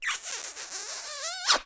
Звук поцелуя с любовью